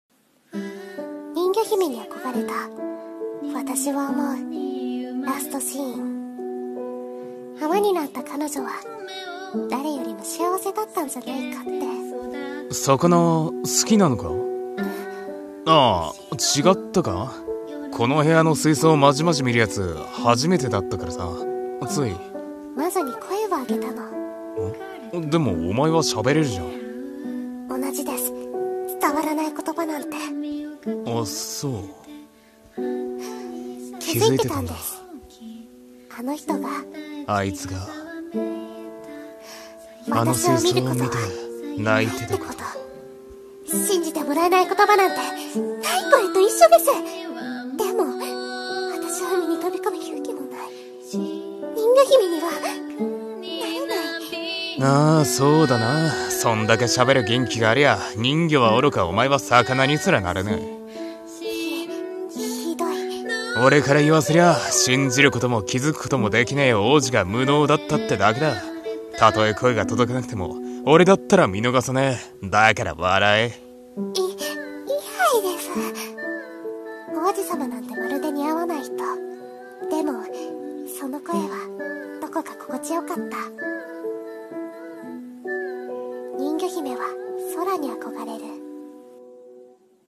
声劇台本